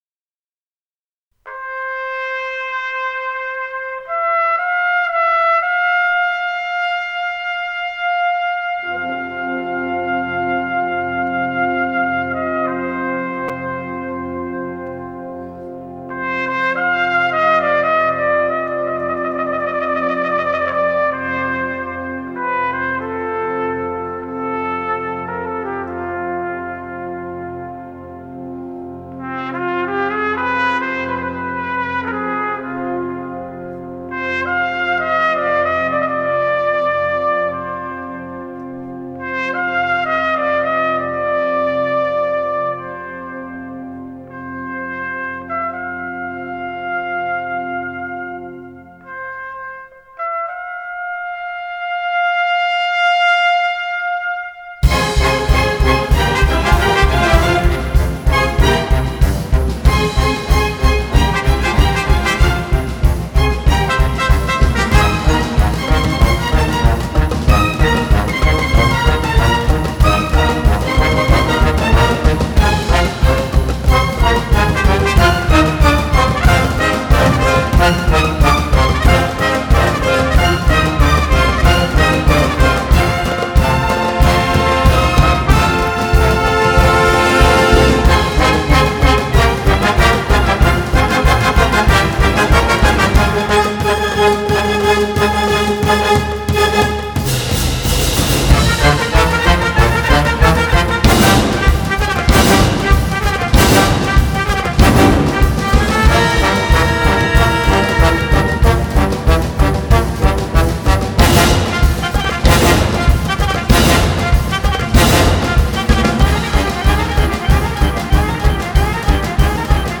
Genre: Balkan Folk, World Music